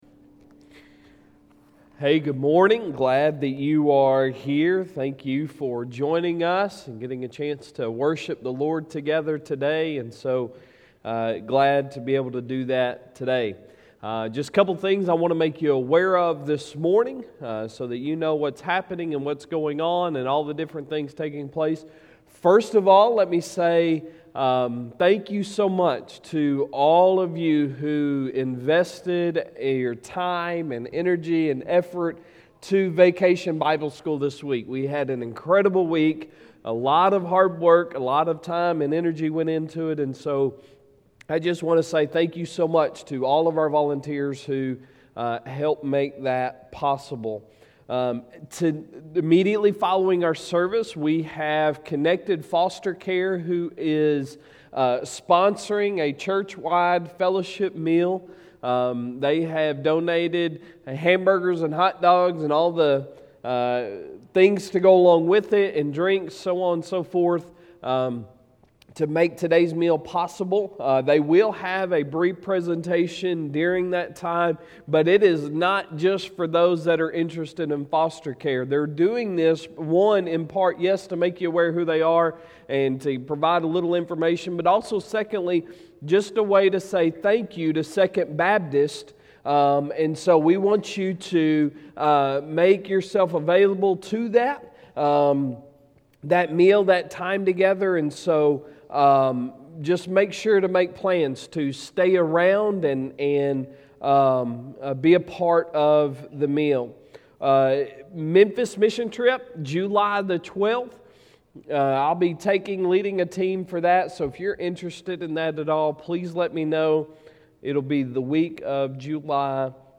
Sunday Sermon June 27, 2021